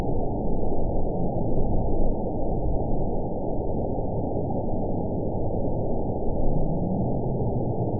event 910766 date 01/31/22 time 09:21:28 GMT (3 years, 3 months ago) score 8.78 location TSS-AB01 detected by nrw target species NRW annotations +NRW Spectrogram: Frequency (kHz) vs. Time (s) audio not available .wav